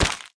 Wep Paperplane Crumble Sound Effect
Download a high-quality wep paperplane crumble sound effect.
wep-paperplane-crumble.mp3